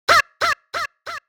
MB Vox (7).wav